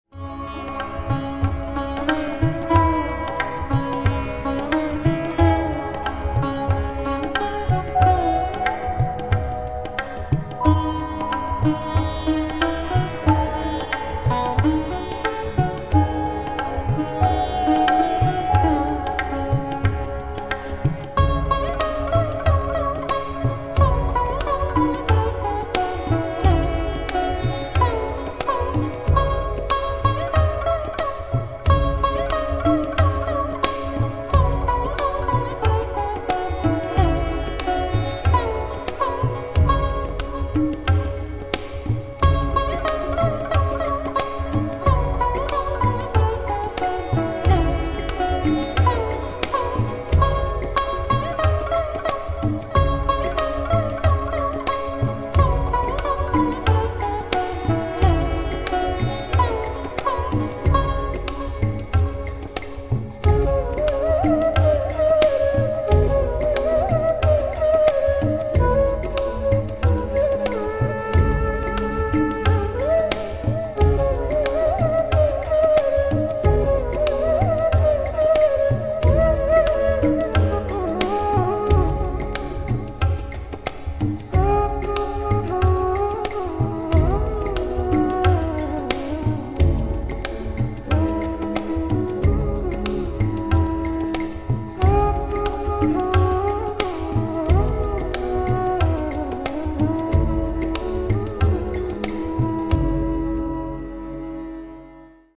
Hudba pro masáže a terapii